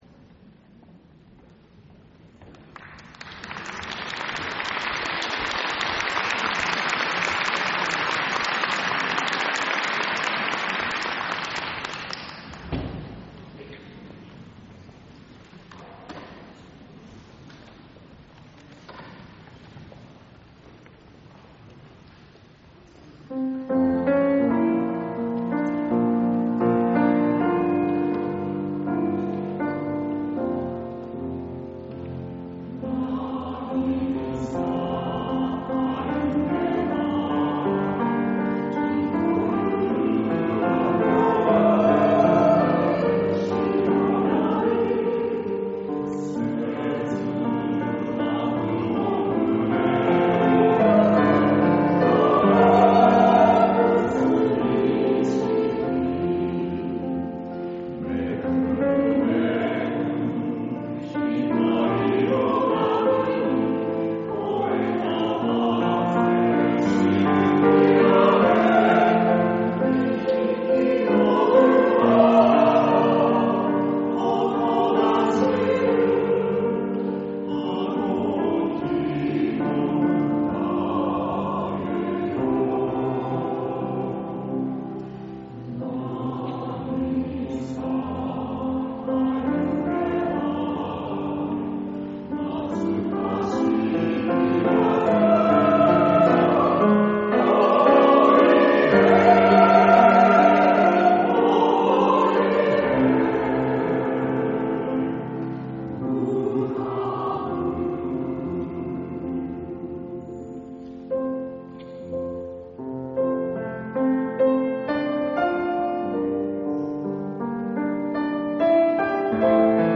第６０回台東区合唱祭が、平成２７年１１月１５日（日）に東京藝術大学奏楽堂で開催されました。
その時の各団体の演奏です。
混声合唱のための組曲「旅」より 佐藤眞 作曲